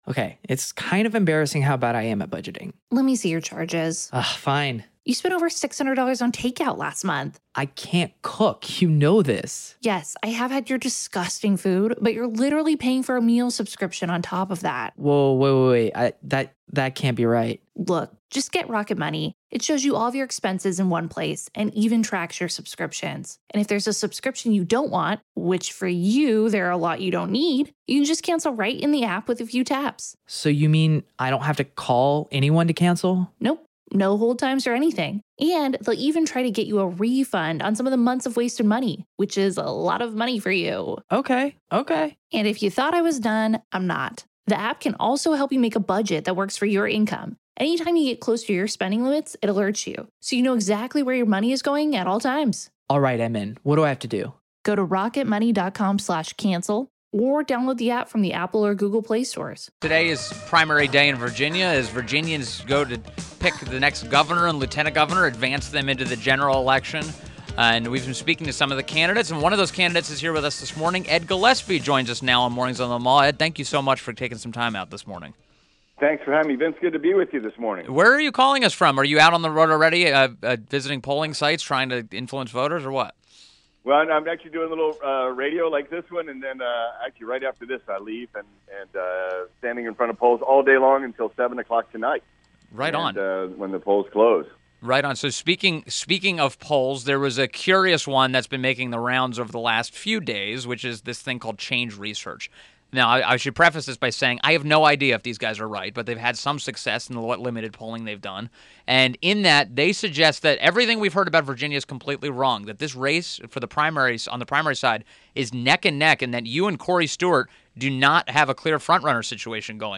WMAL Interview - ED GILLESPIE 06.13.17
ED GILLESPIE - Republican gubernatorial candidate Topic : Virginia Primary Elections